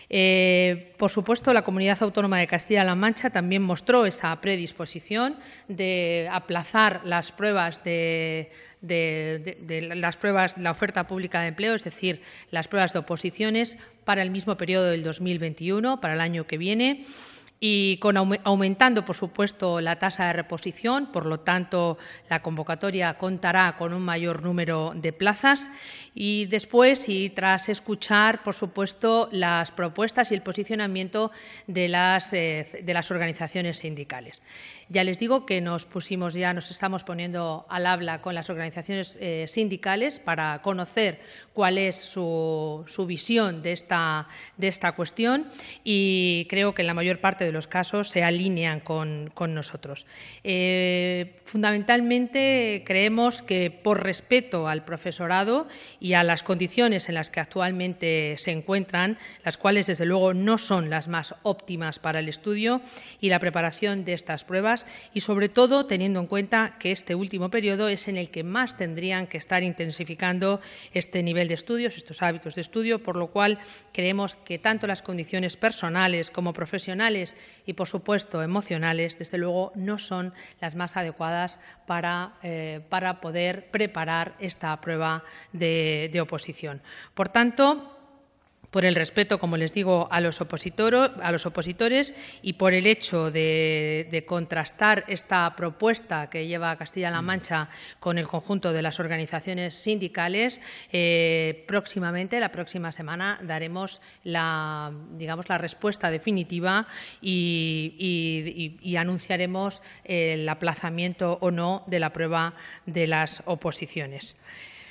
>> La consejera de Educación, Cultura y Deportes, Rosa Ana Rodríguez, ha informado de esta decisión después de la celebración del Consejo de Gobierno
(DIRECTO) Comparecencia informativa de Consejo de Gobierno Extraordinario